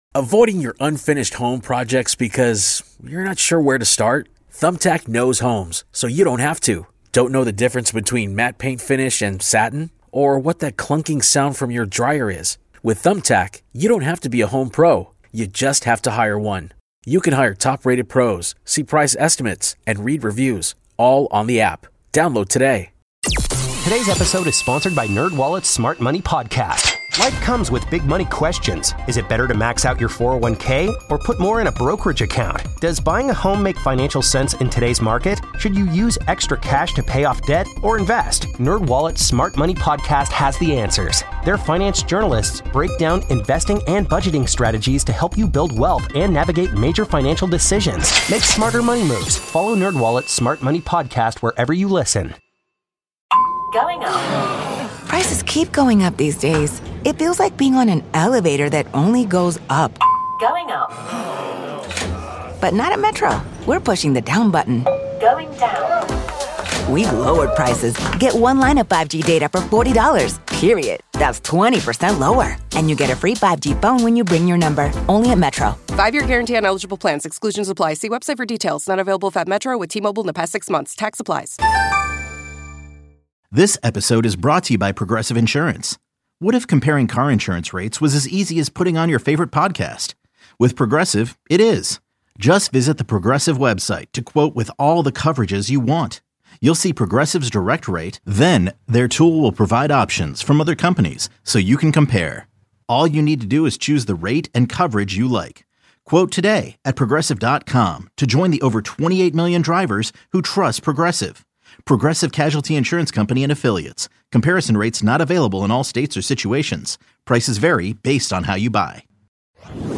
takes a comprehensive look at local and national sports from a gambling perspective with insightful guests, including Vegas oddsmakers. The show airs at 8 a.m. on Saturdays on 670 The Score.